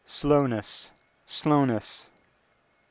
Transcription Practice:  English Dialects and Allophones